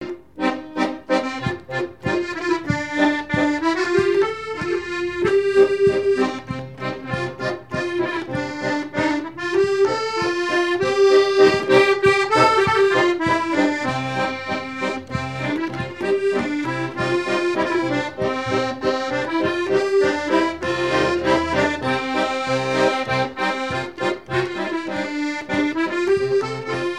Usage d'après l'informateur circonstance : fiançaille, noce ;
Genre laisse
Pièce musicale inédite